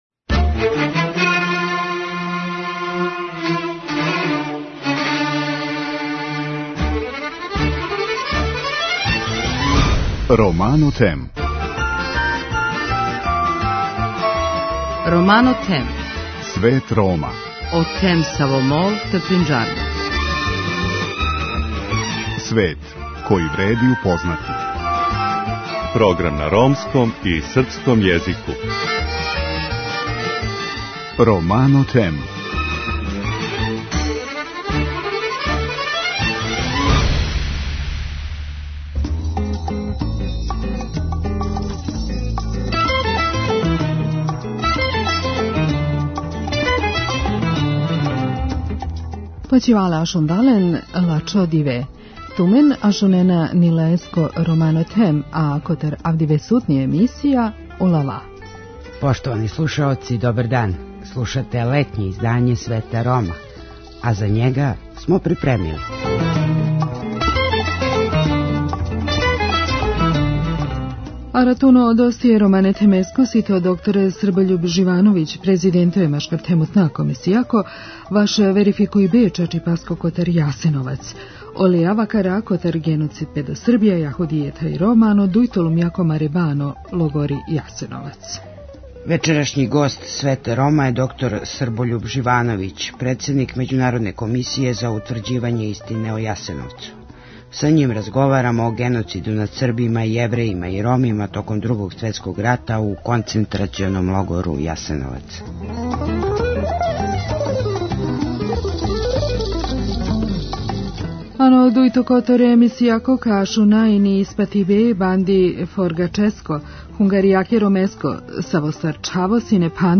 преузми : 7.16 MB Romano Them Autor: Ромска редакција Емисија свакодневно доноси најважније вести из земље и света на ромском и српском језику. Бави се темама из живота Рома, приказујући напоре и мере које се предузимају за еманципацију и интеграцију ове, највеће европске мањинске заједнице.